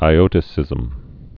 (ī-ōtə-sĭzəm)